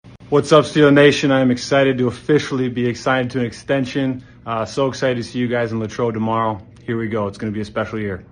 The team officially announced TJ Watt’s new contract yesterday and released a video of a smiling Watt with a message for Steelers fans.